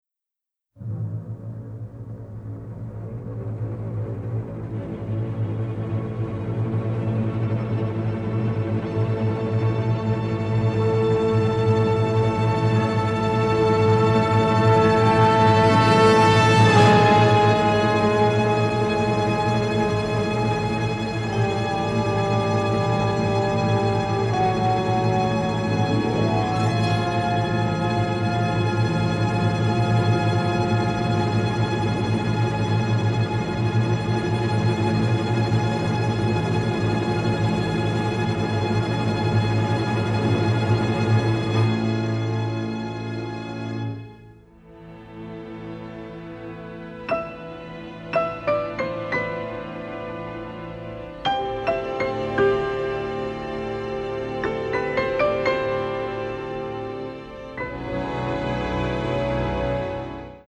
Romantic and melancholic